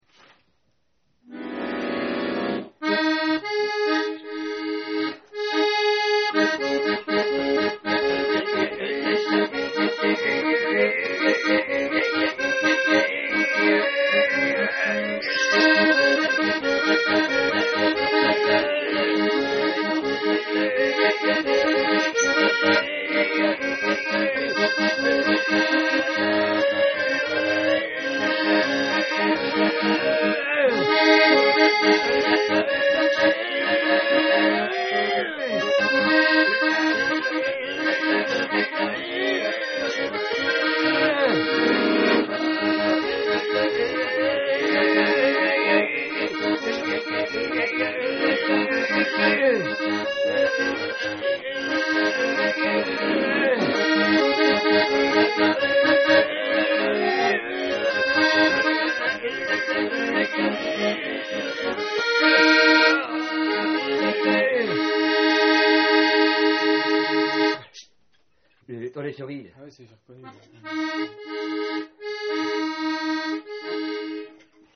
Raw tenderness.